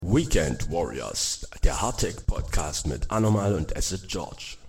Das ganze in gewohnt ungestellter Manier, mit viel Witz aber auch mit offenen Worten.